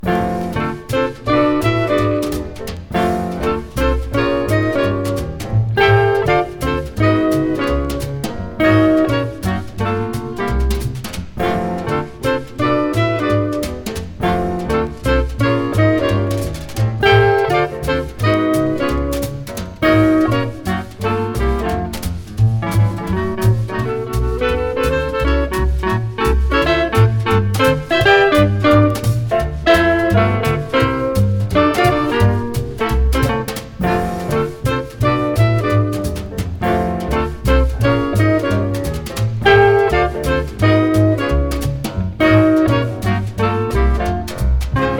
Jazz, Cool Jazz　Japan　12inchレコード　33rpm　Mono